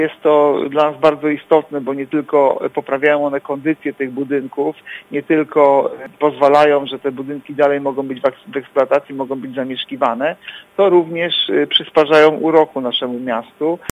Mówi Artur Urbański zastępca prezydenta Ełku.